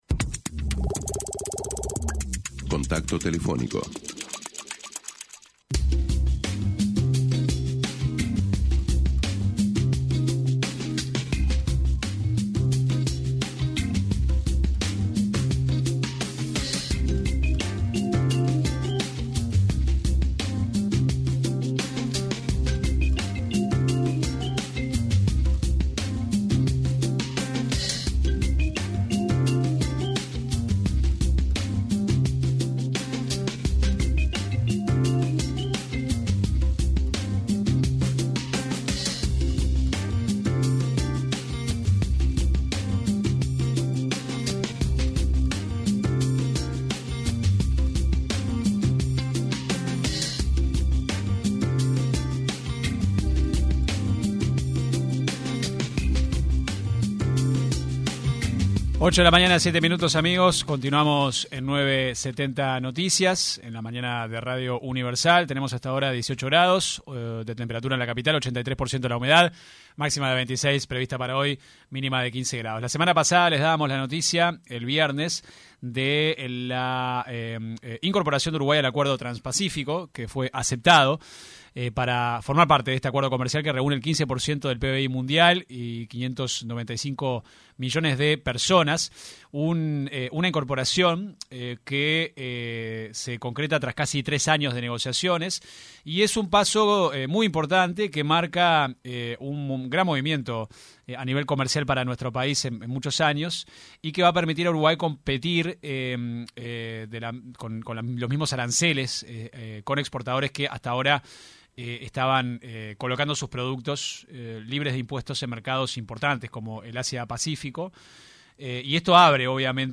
El ex subsecretario del Ministerio de Relaciones Exteriores, Nicolás Albertoni en entrevista con 970 Noticias, “resalto” la politica que viene llevando la Cancillería y “ve con buenos ojos” que “ha continuado” las propuestas del gobierno anterior.